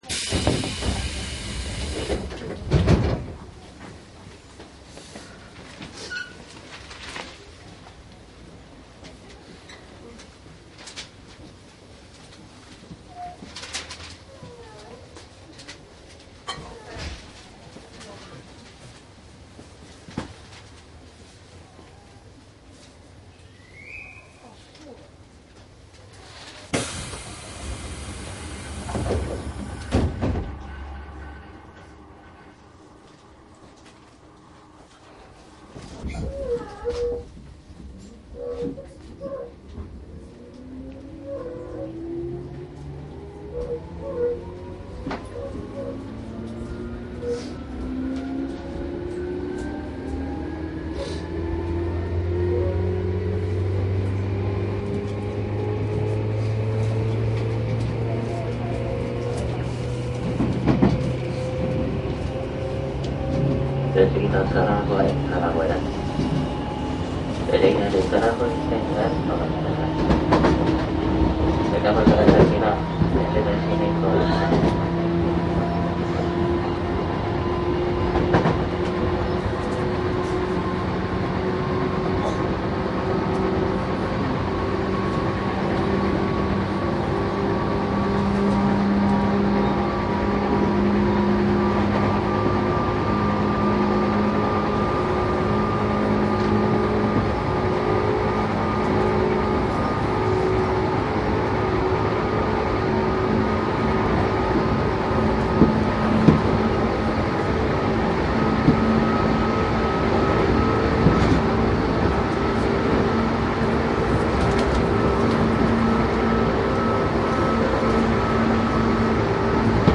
東武東上線 急行 10000系・10030系走行音CD
主に下りの急行を録音しています。
線路の低騒音化が始まる前の録音です。待避線や分岐線のジョイント音が楽しめます。
録音はすべて空調未稼働での環境で録音しています。
マスター音源はデジタル44.1kHz16ビット（マイクＥＣＭ959）で、これを編集ソフトでＣＤに焼いたものです。